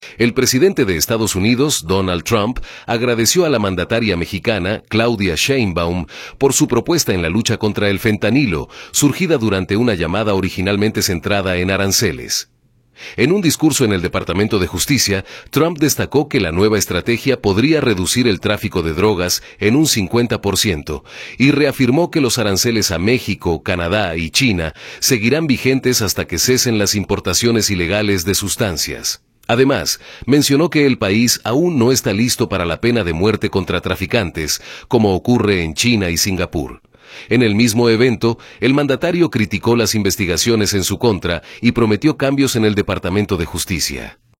El presidente de Estados Unidos, Donald Trump, agradeció a la mandataria mexicana, Claudia Sheinbaum, por su propuesta en la lucha contra el fentanilo, surgida durante una llamada originalmente centrada en aranceles. En un discurso en el Departamento de Justicia, Trump destacó que la nueva estrategia podría reducir el tráfico de drogas en un 50% y reafirmó que los aranceles a México, Canadá y China seguirán vigentes hasta que cesen las importaciones ilegales de sustancias. Además, mencionó que el país aún no está listo para la pena de muerte contra traficantes, como ocurre en China y Singapur.